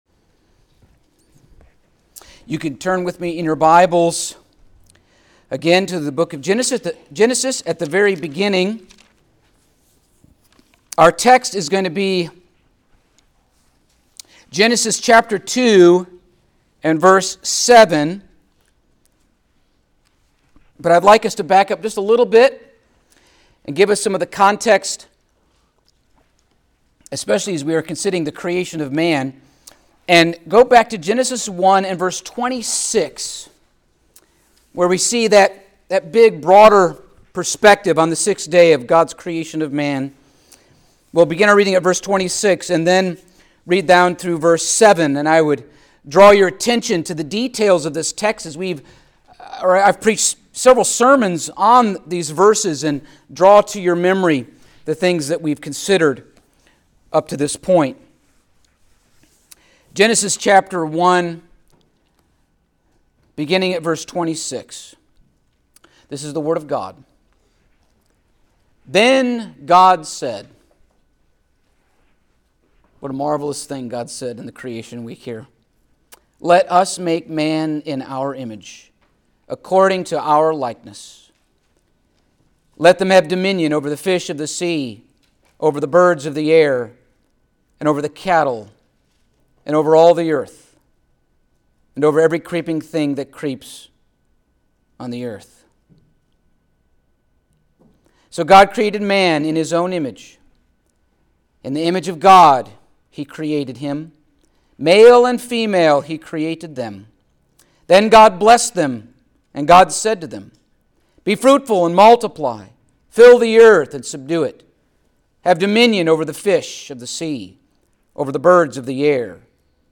Service Type: Sunday Morning Topics: Creation , Regeneration , The Doctrine of God , The Doctrine of Man